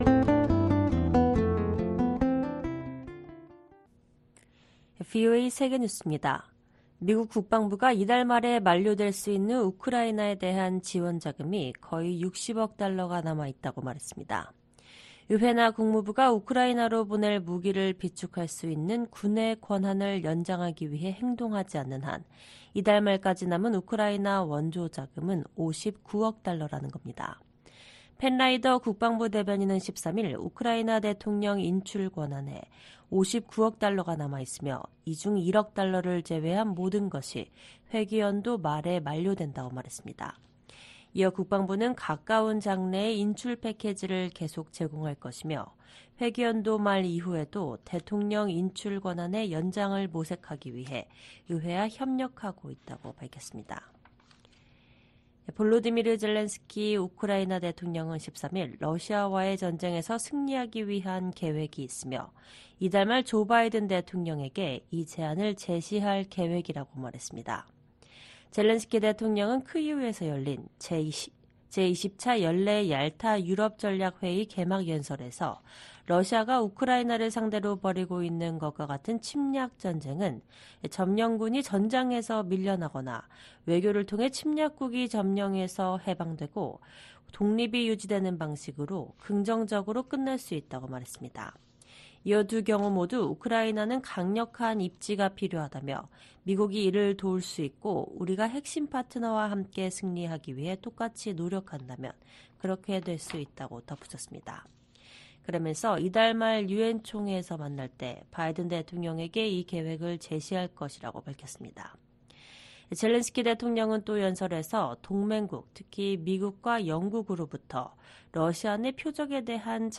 VOA 한국어 방송의 토요일 오후 프로그램 3부입니다.